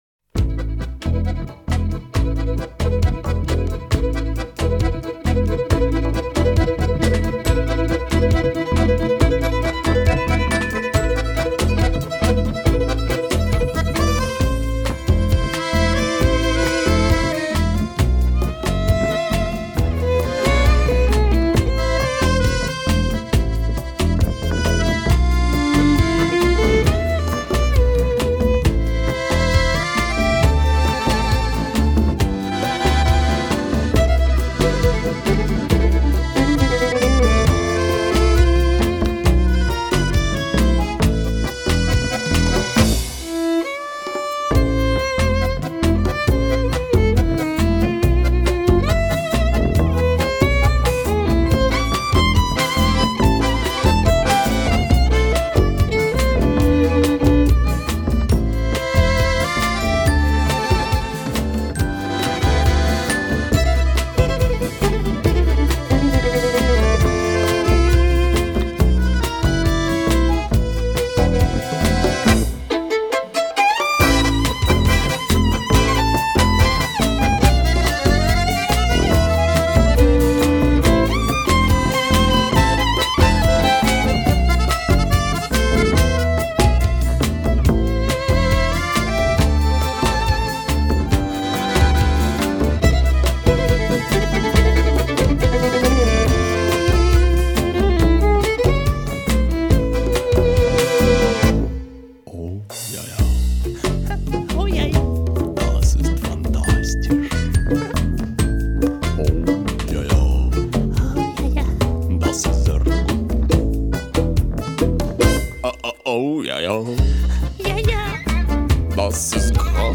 контрабас, вокал.
аккордеон, вокал.
скрипка, вокал.
барабаны, перкуссия.